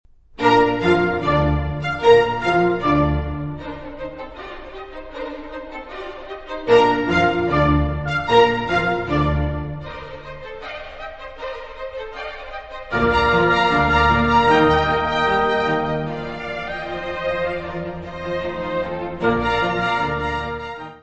Notes:  Gravado no Concert Hall, New Broadcasting House, Manchester, de 26 a 27 de Outubro, 1993; Disponível na Biblioteca Municipal Orlando Ribeiro - Serviço de Fonoteca
Music Category/Genre:  Classical Music
Andantino spiritoso.